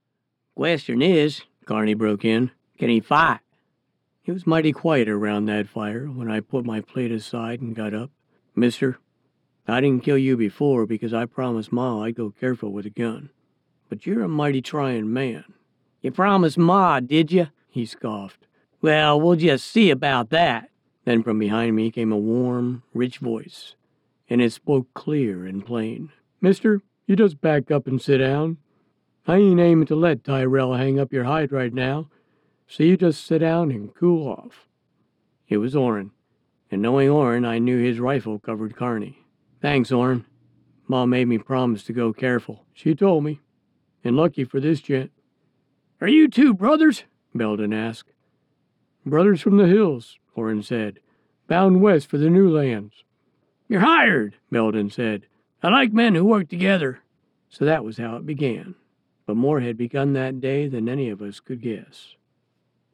AUDIOBOOK FICTION
AUDIOBOOK FICTION Multi Character.mp3